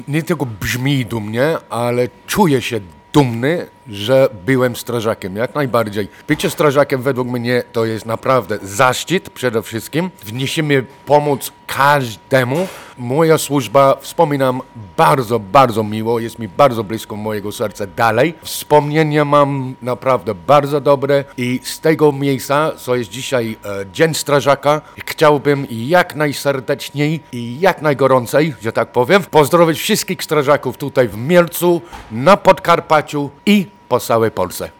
Czy bycie strażakiem brzmi dumnie, zapytaliśmy strażaka w stanie spoczynku a dziś gaszącego uczucie głodu, Kevina Aistona.